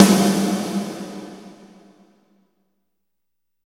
12 WET SNR-L.wav